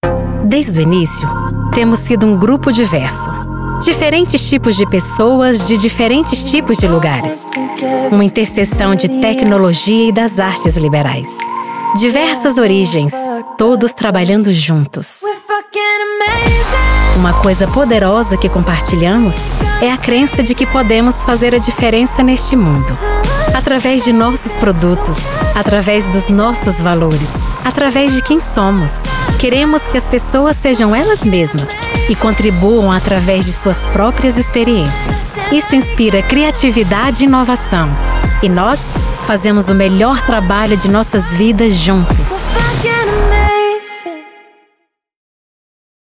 A powerful voice with good diction.
Sprechprobe: Industrie (Muttersprache):
I have a soft and welcoming tone of voice, but one that conveys confidence, energy and credibility.